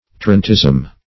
Tarantism \Tar"ant*ism\, n. [It. tarantismo: cf. F. tarentisme.